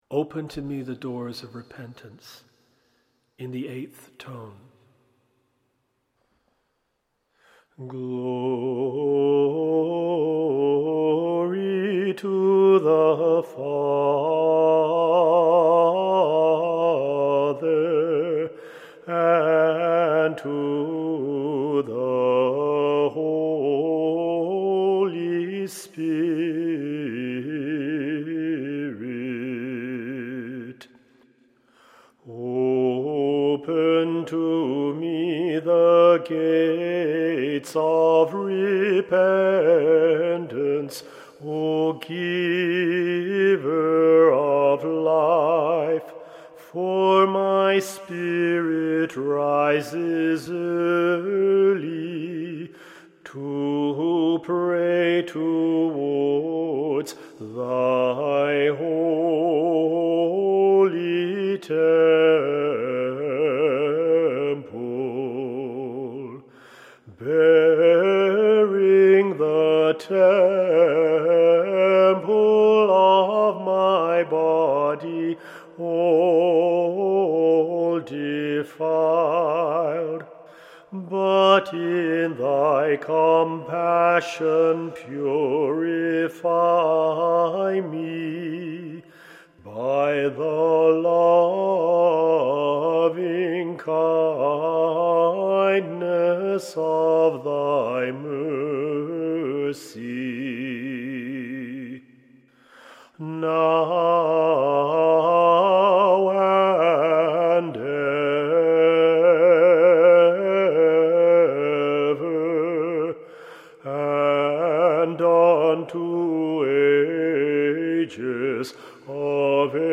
Tone 8